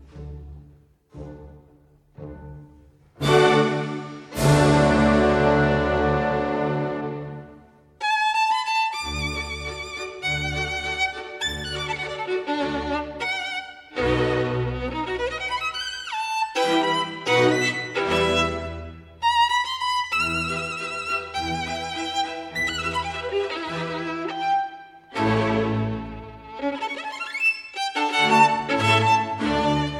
"templateExpression" => "Musique orchestrale"